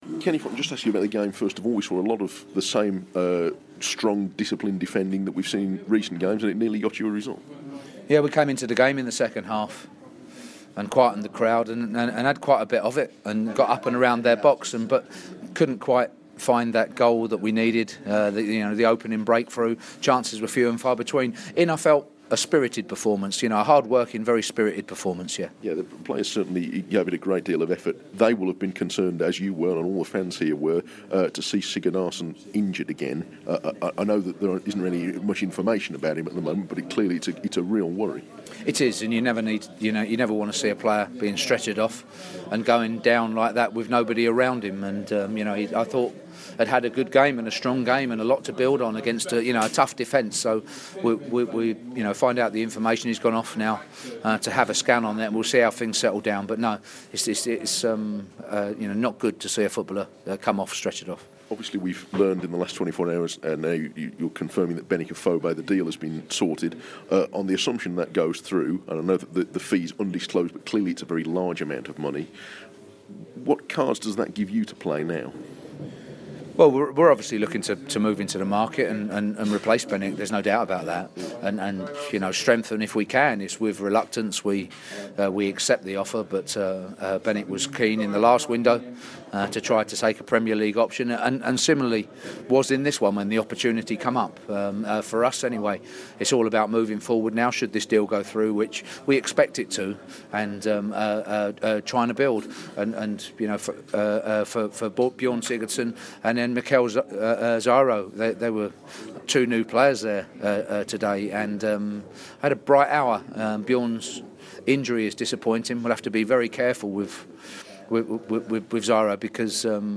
Wolves boss Kenny Jackett speaks to BBC WM after the FA cup defeat at West Ham on the day the club announced they'd accepted an offer from Bournemouth for Benik Afobe.